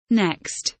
next kelimesinin anlamı, resimli anlatımı ve sesli okunuşu